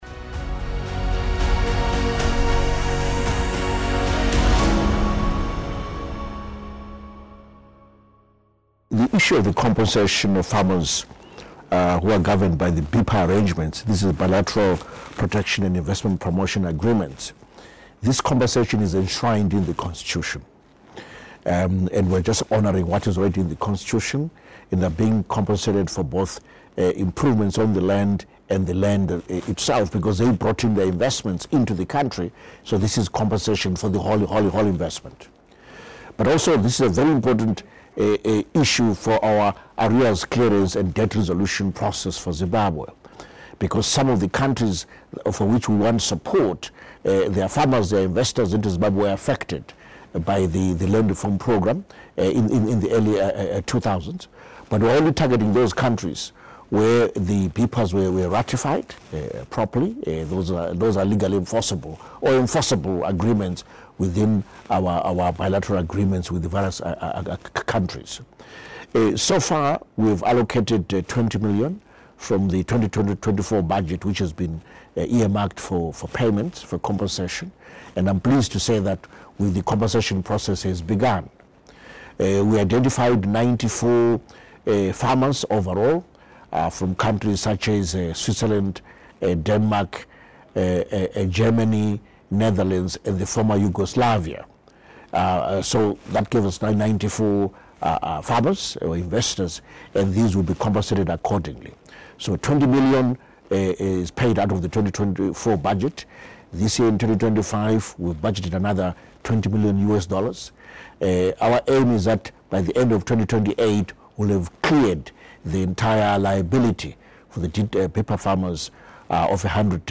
Zimbabwe Minister of Finance Hon. Prof. Mthuli Ncube Speaks on BIPPA Investors Compensation (Source: Zimbabwe Ministry of Finance, Economic Development and Investment Promotion | 1 year ago)